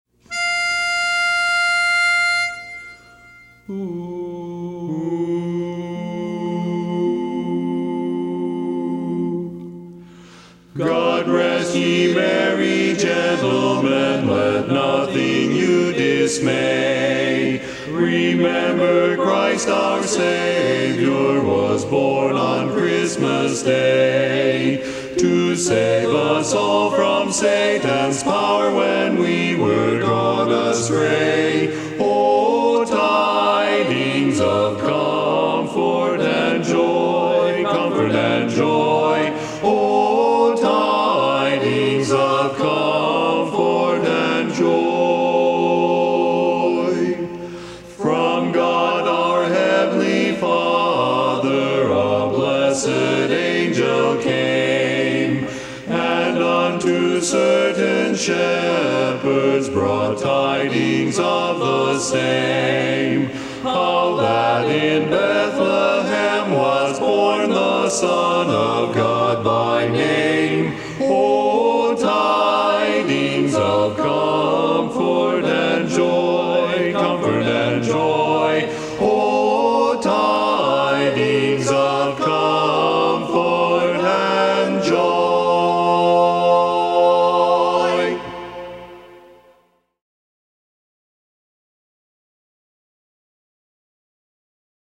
Christmas Songs
Barbershop
Bass